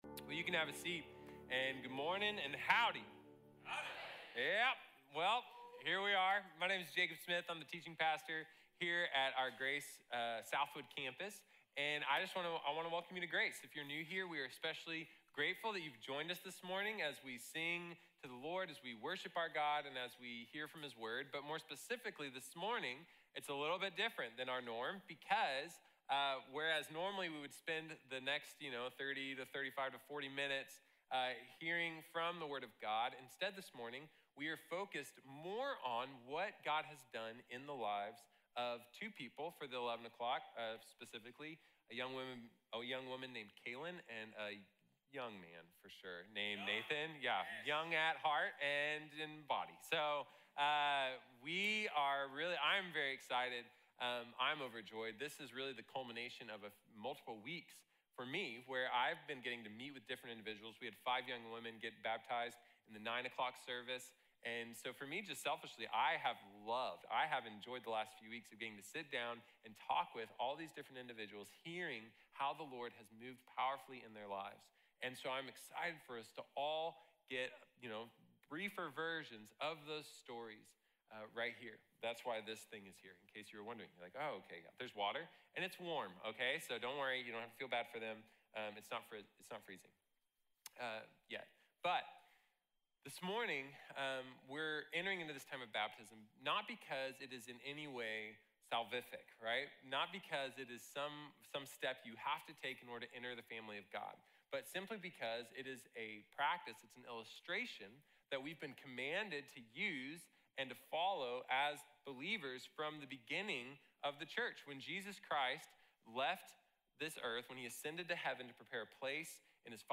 Fall 2021 Baptisms | Sermon | Grace Bible Church